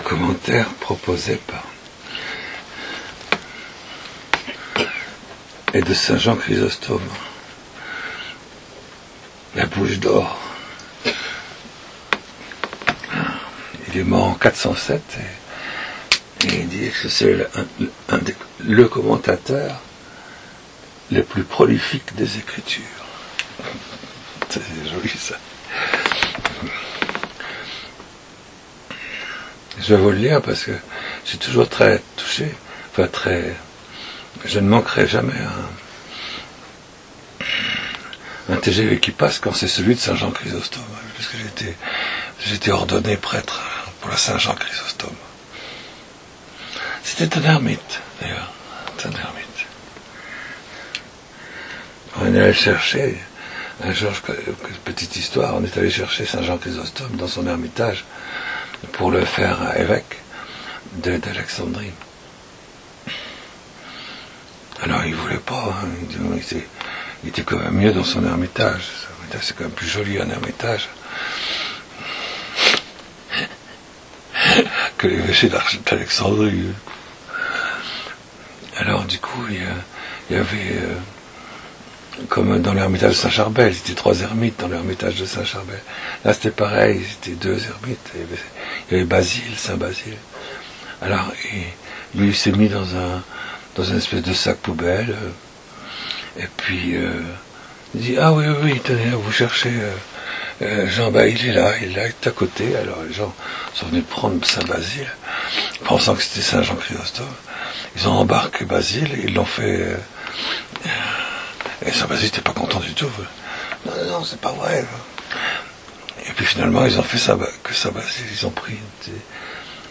23_3Homelie.mp3